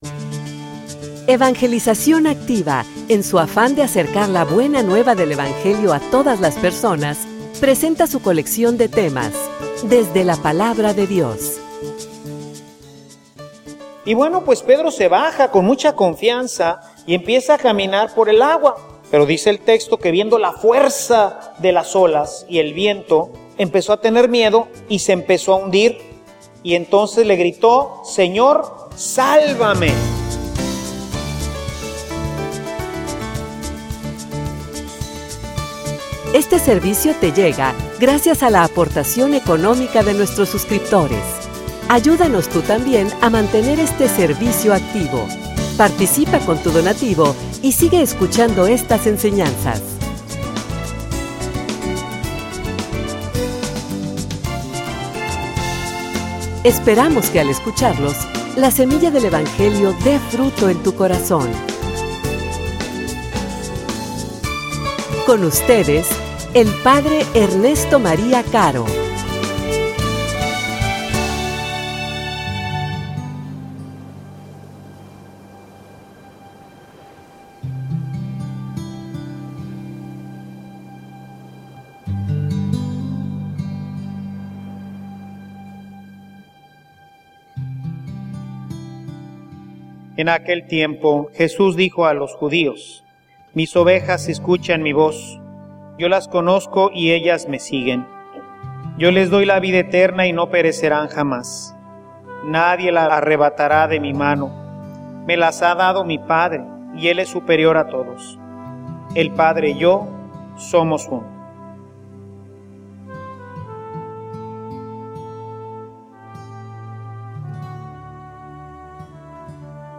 homilia_Seguros_en_sus_manos.mp3